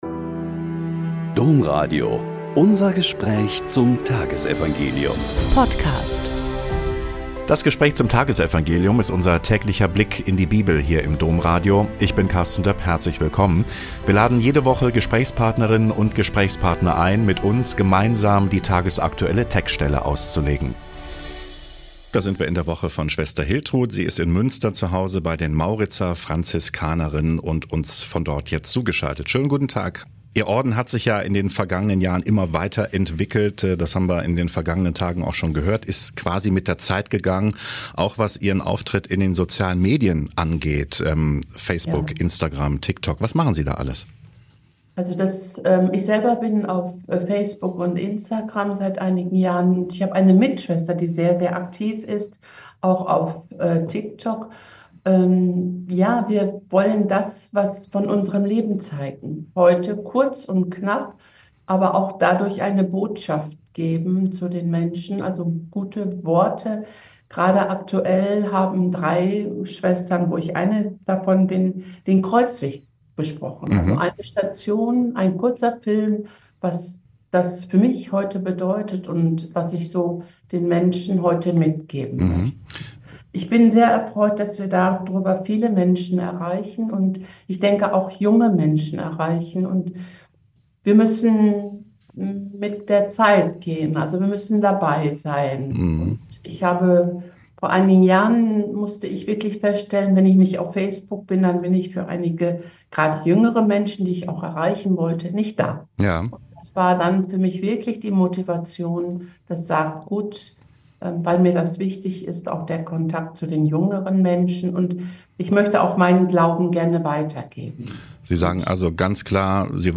Joh 8,31-42 - Gespräch